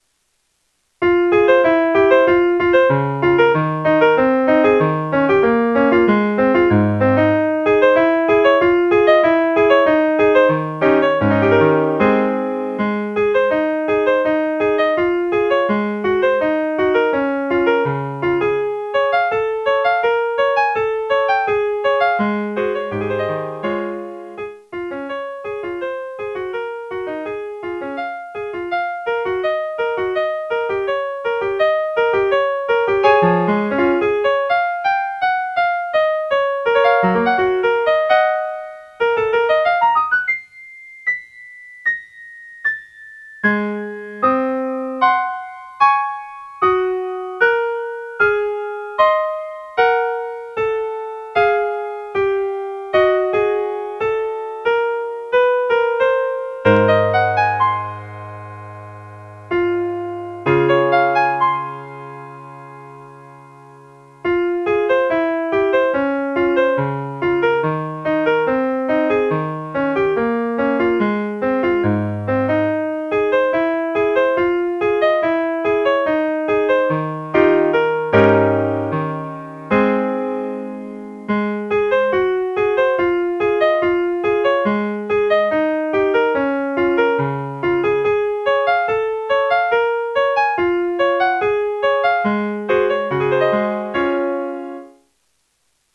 피아노 독주곡